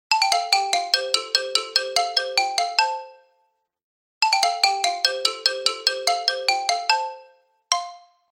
Catégorie Telephone